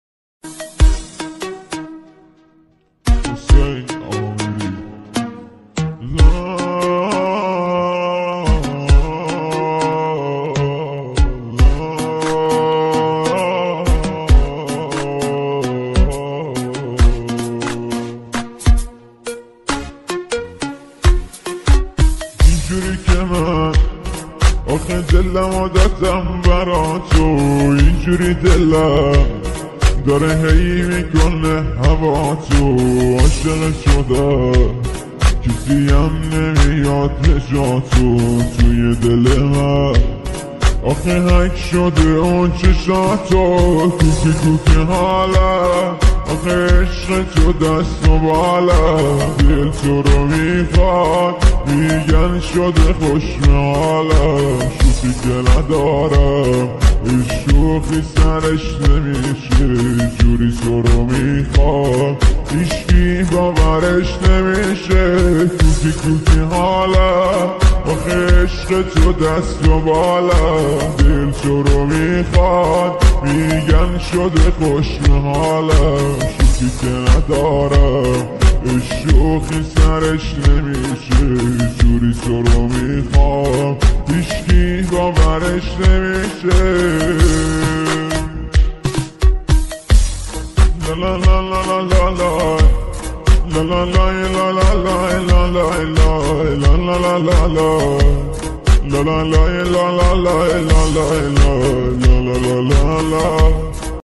Irani song